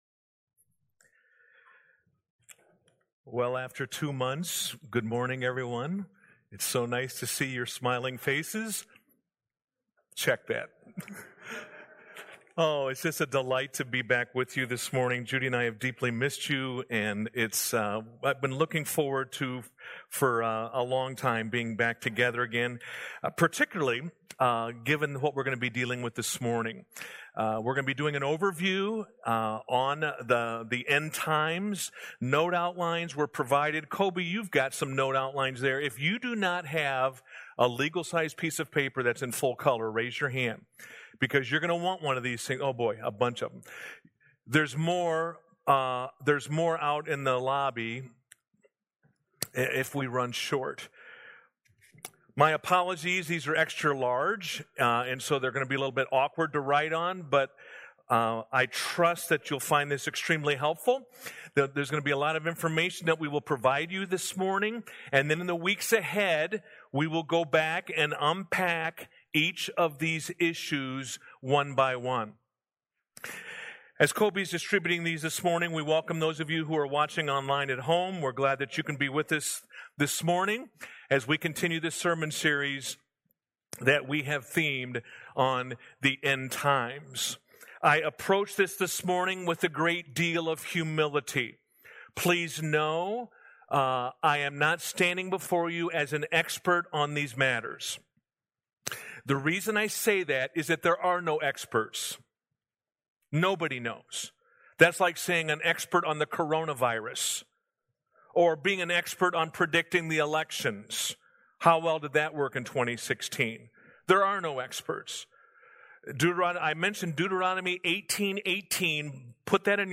Sermons | California Road Missionary Church